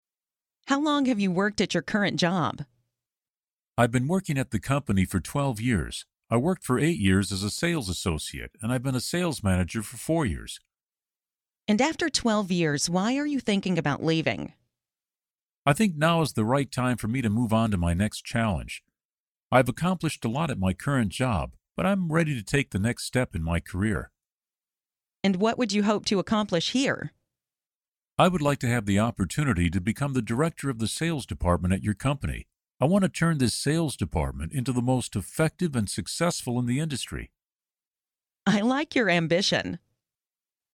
Learn different ways to answer the interview question 'How long have you worked at your current job?', listen to an example conversation, and study example sentences